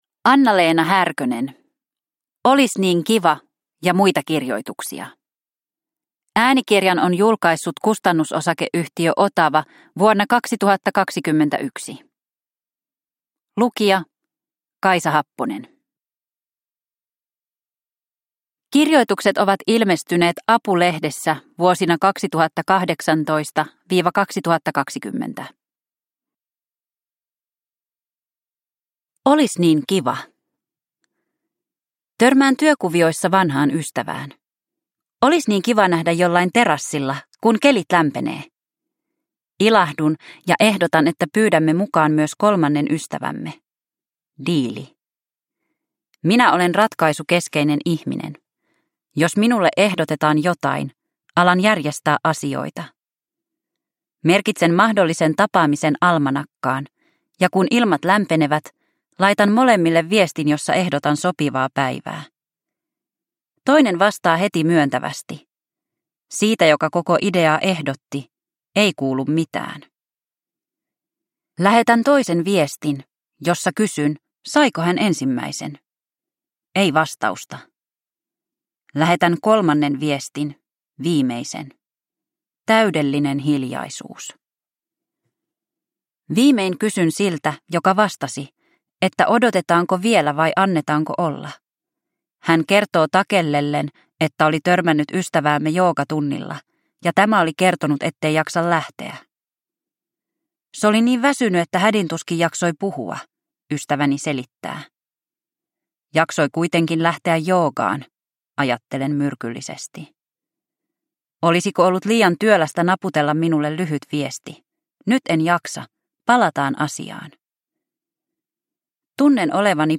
Olis niin kiva – Ljudbok